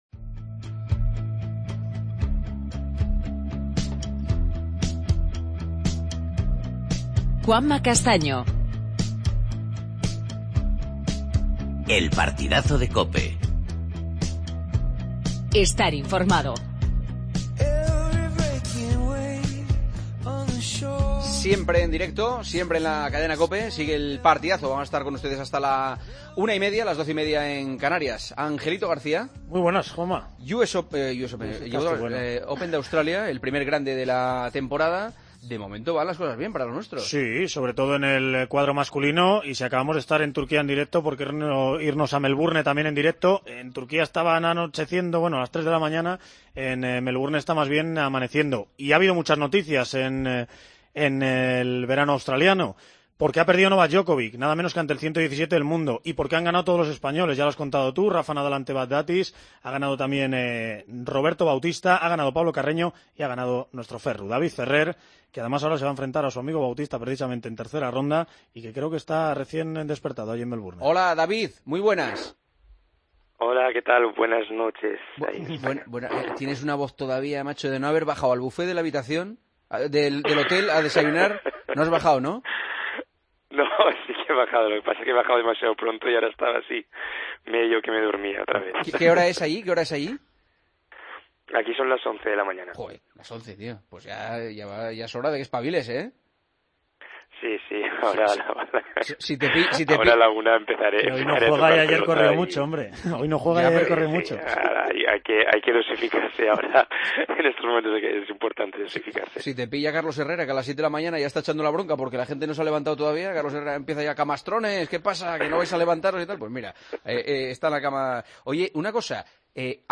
AUDIO: Entrevista a David Ferrer. Hablamos con el jugador de la selección española de balonmano, Raul Entrerríos.